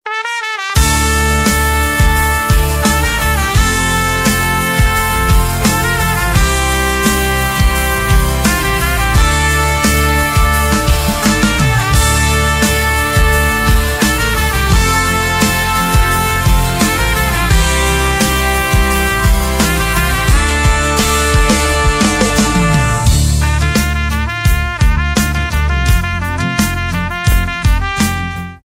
духовые
pop rock
без слов , труба